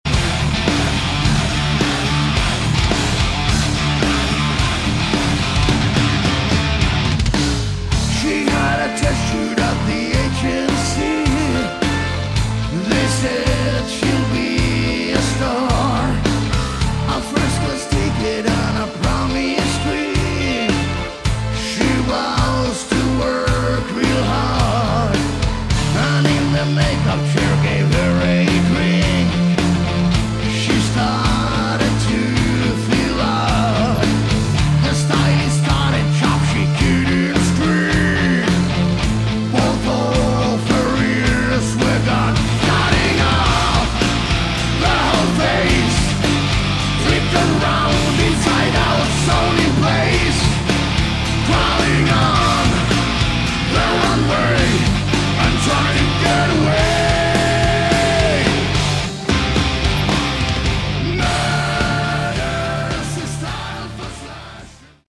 Category: Theatre Metal
vocals
guitars
bass
drums
keyboards